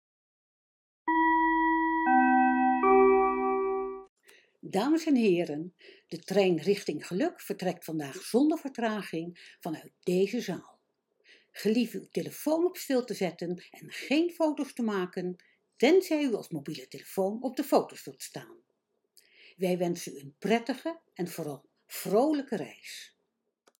Een ceremonie-opening voor een hoofdconducteur met perron-aankondiging?